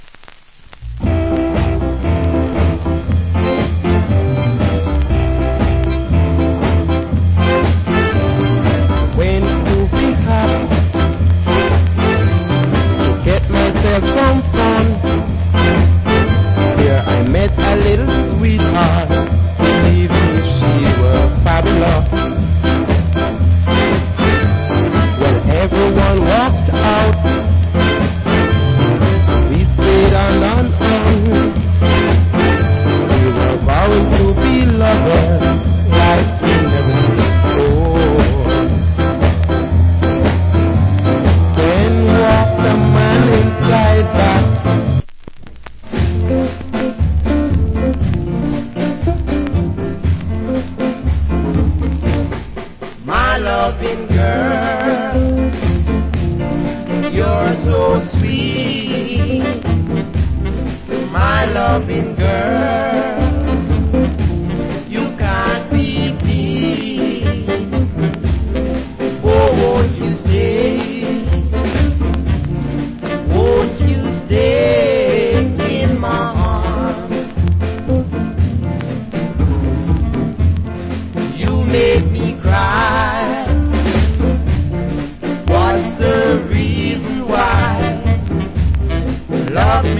Ska Vocal.